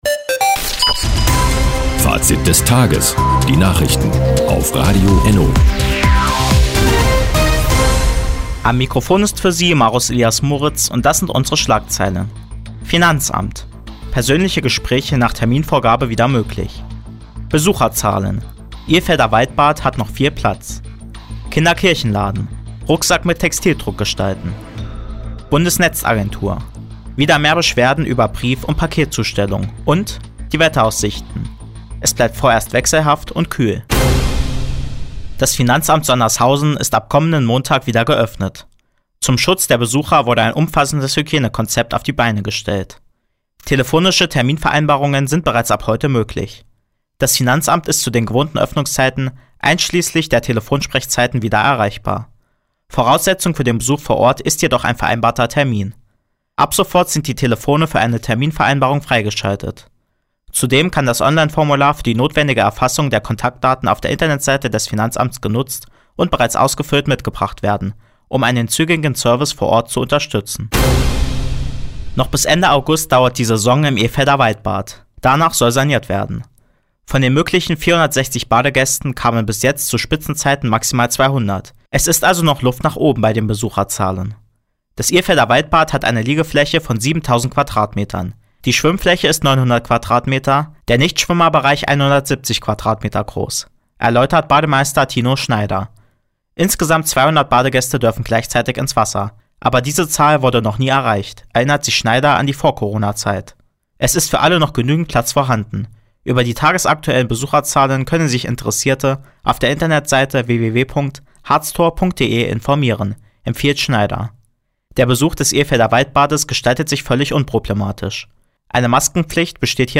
Di, 17:03 Uhr 07.07.2020 N Fazit des Tages Anzeige Refinery (lang) Seit Jahren kooperieren die Nordthüringer Online-Zeitungen und das Nordhäuser Bürgerradio ENNO. Die tägliche Nachrichtensendung ist jetzt hier zu hören.